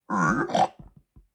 Play, download and share Piglin 2 original sound button!!!!
piglin-2.mp3